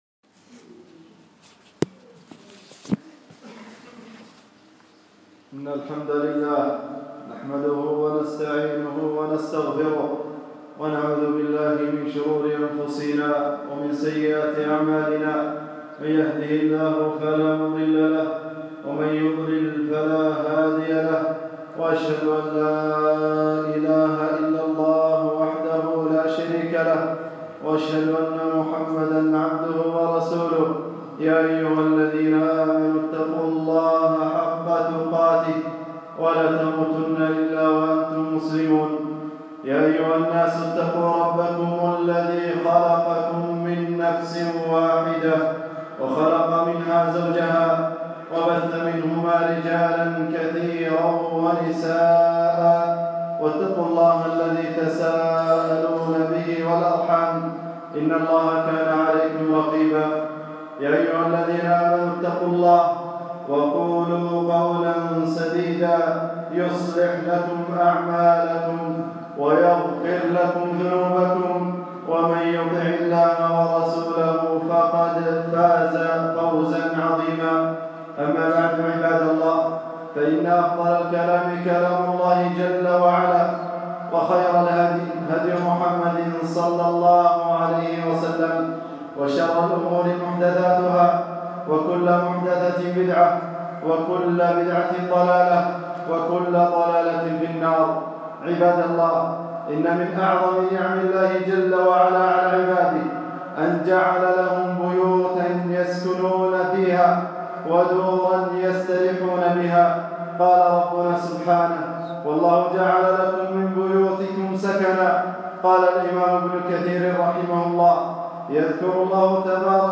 خطبة - إصلاح البيوت وخطورة وسائل التواصل 7-4-1440 هــ